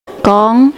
Woman